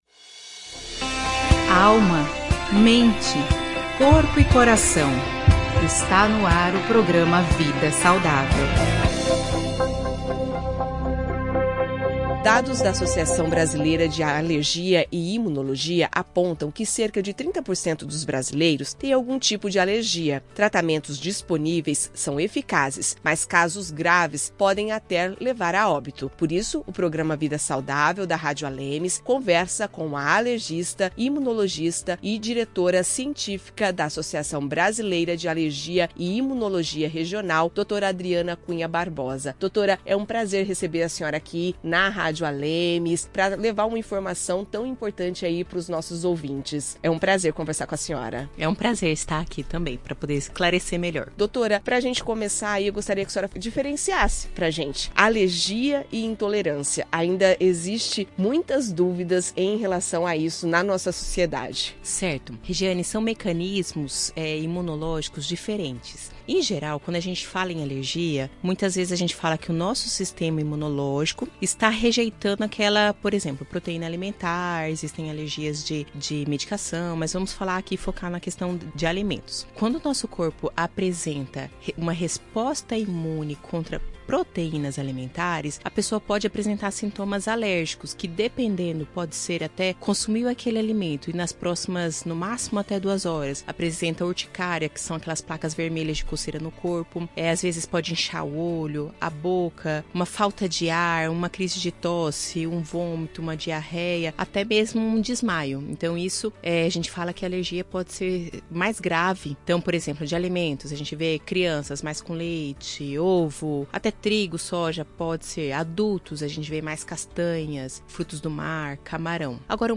Dados da Associação Brasileira de Alergia e Imunologia (ASBAI) apontam que cerca de 30% dos brasileiros têm algum tipo de alergia. Tratamentos disponíveis são eficazes, mas casos graves podem até levar a óbito. Por isso o programa vida saudável da Rádio ALEMS conversa com a alergista